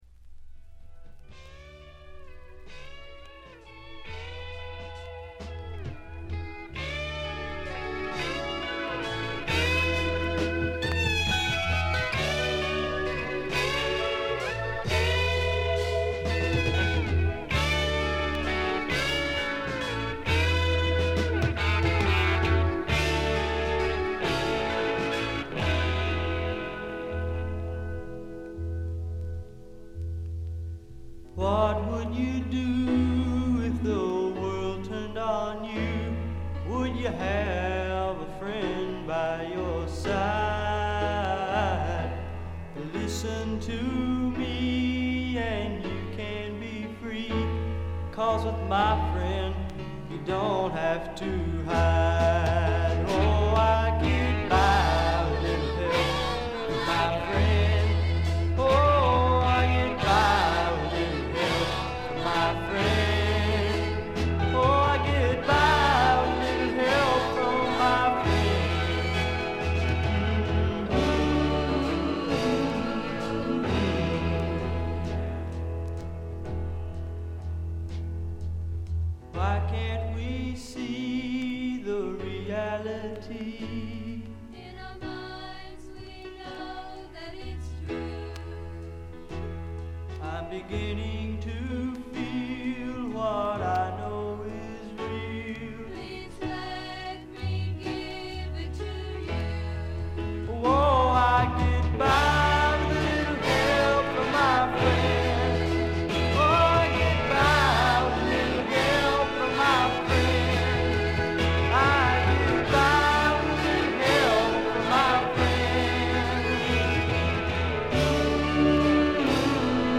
ところどころでチリプチ、散発的なプツ音少々。
試聴曲は現品からの取り込み音源です。